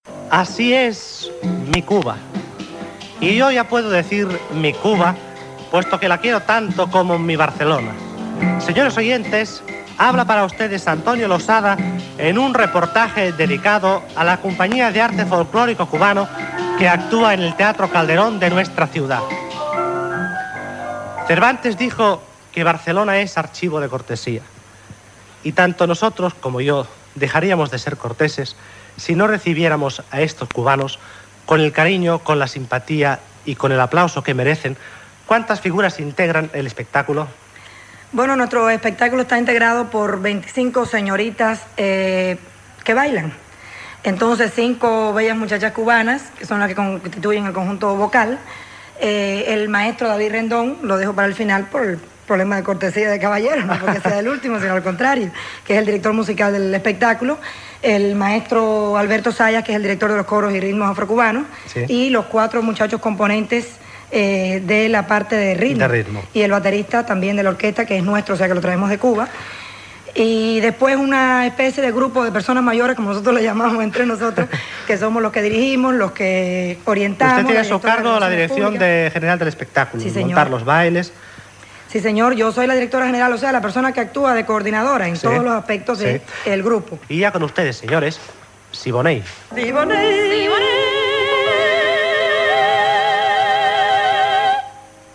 Fragment d'un reportatge sobre el Grupo Folklórico Cubano que actua a Barcelona presentant l'espectacle "Así es mi Cuba"